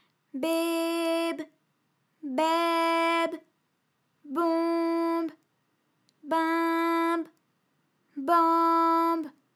ALYS-DB-001-FRA - First, previously private, UTAU French vocal library of ALYS
behbaibonbinbanb.wav